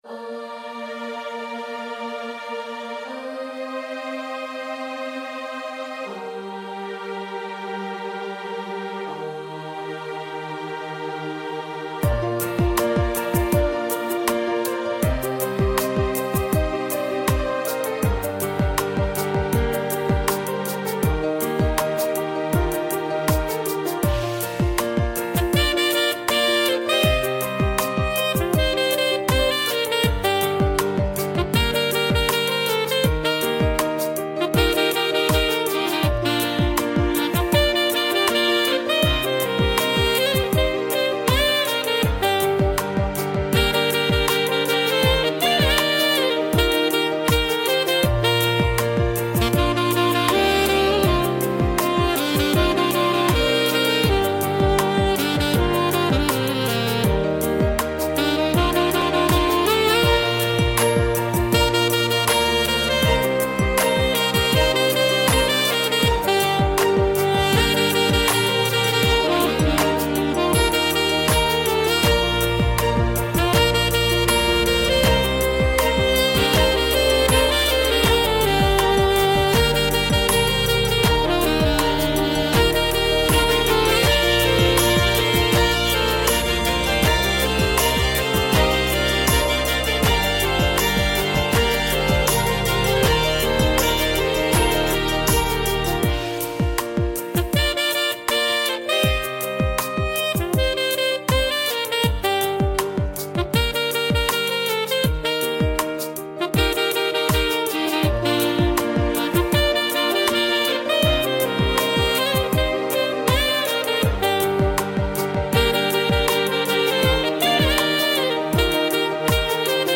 radiomarelamaddalena / STRUMENTALE / SAX /